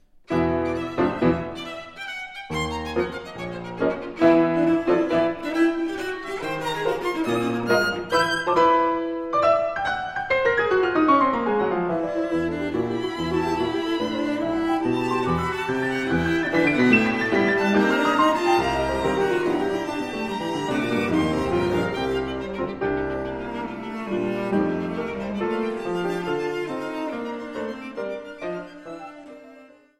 Zahlreiche Ersteinspielungen